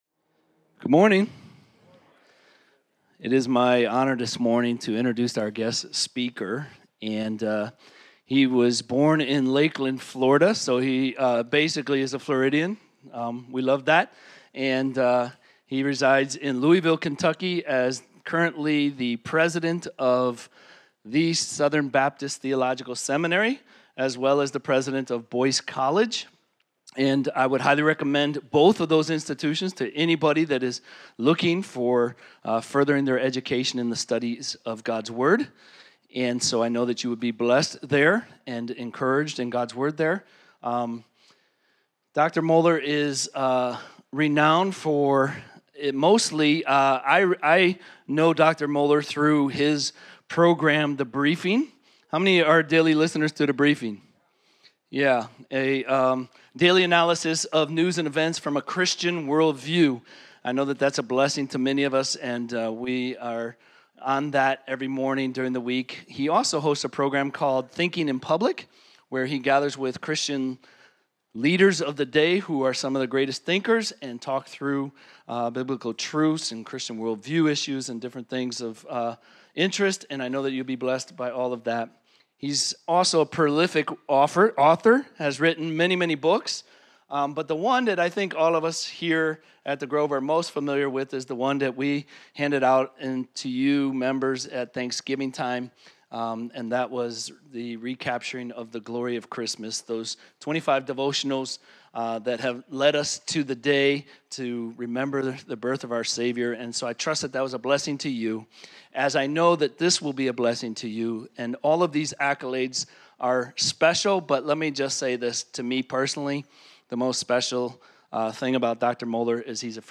Guest Speaker – Dr. Albert Mohler
Home Sermons Guest Speaker – Dr. Albert Mohler Guest Speaker – Dr. Albert Mohler December 29, 2024 Books: Acts , Isaiah Speakers: Dr. Albert Mohler Download Save MP3 Scripture References Acts 17 Related Isaiah 44 Related